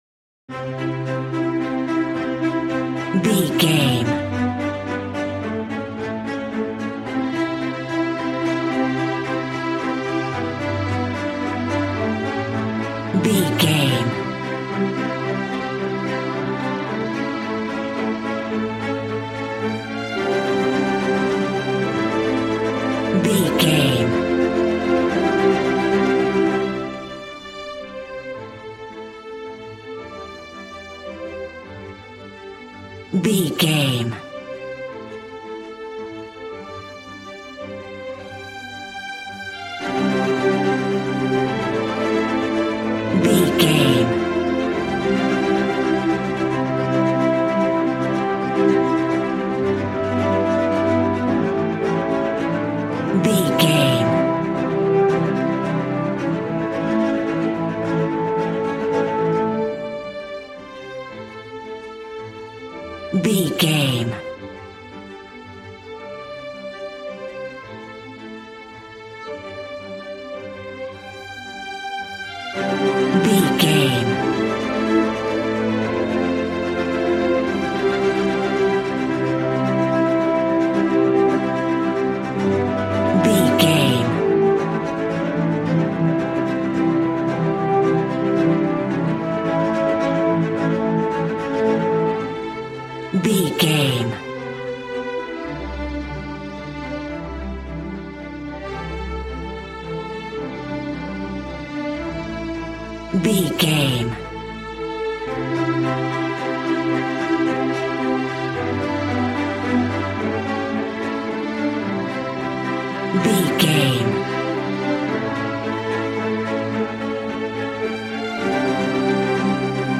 A classical music mood from the orchestra.
Regal and romantic, a classy piece of classical music.
Aeolian/Minor
regal
cello
violin
strings